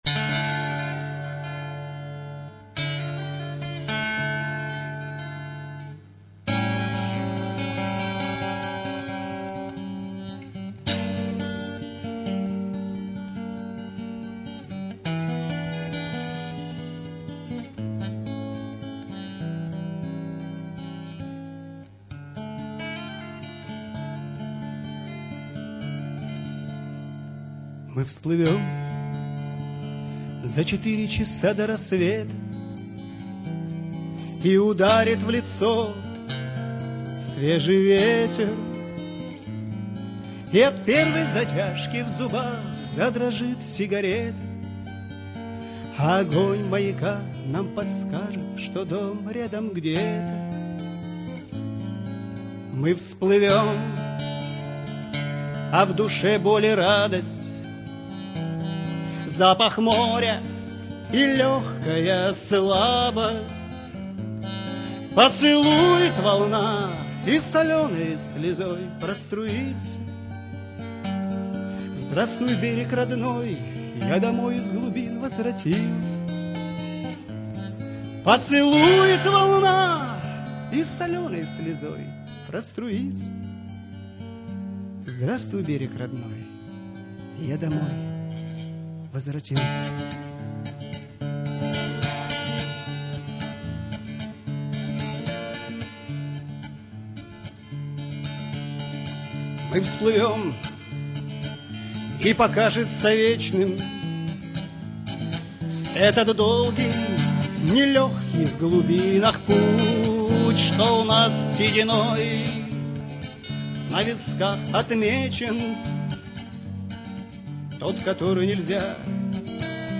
Авторская песня